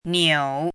怎么读
niǔ